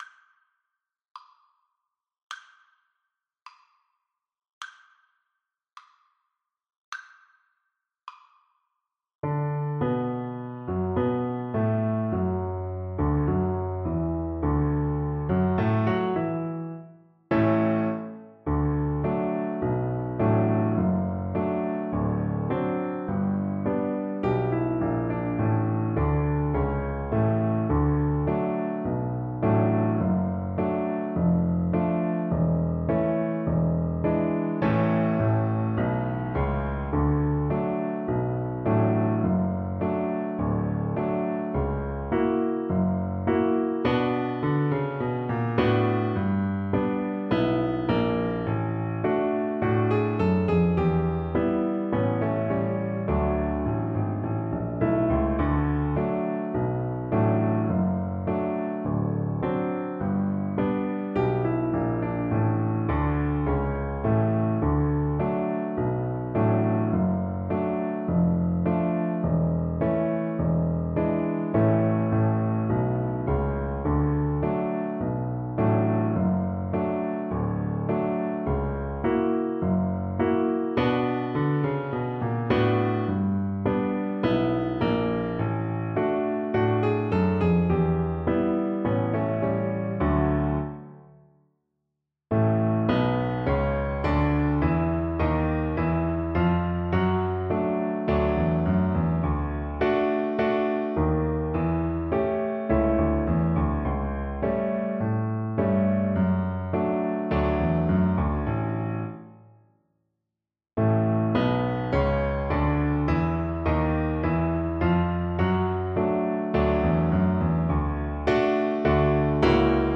Violin
2/4 (View more 2/4 Music)
Slow March Tempo = 80
Jazz (View more Jazz Violin Music)